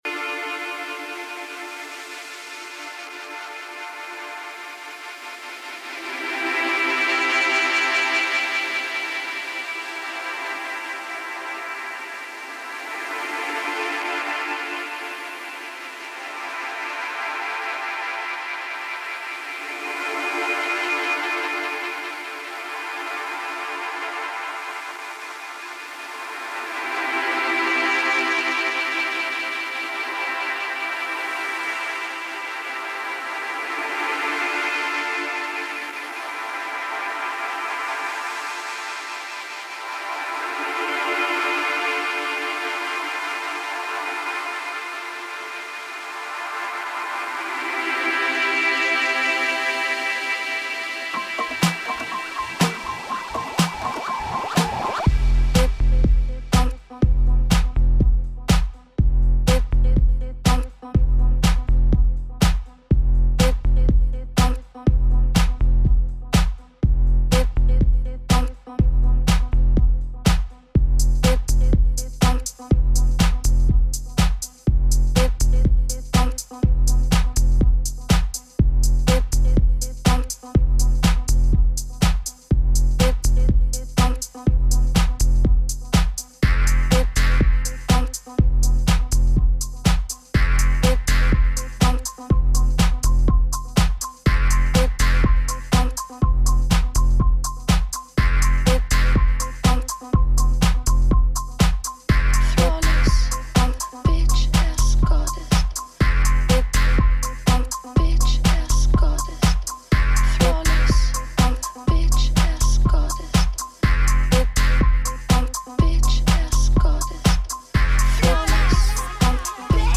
This mix was recorded at home on Ableton.